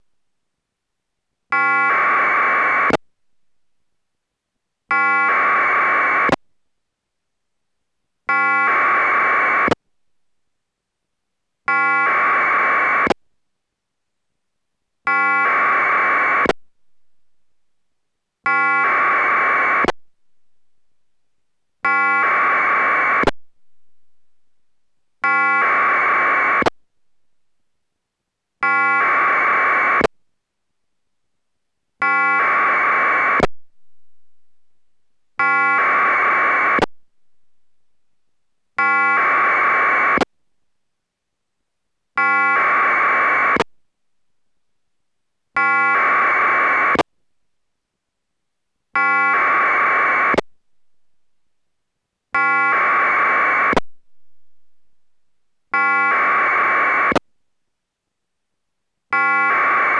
AFSK SRLL 2.0のサンプルサウンドを用意いたしました．
AFSK SRLL 2.0 Sample Sound(96Bytes)
AFSK_SRLL2_SampleSound(96Bytes).wav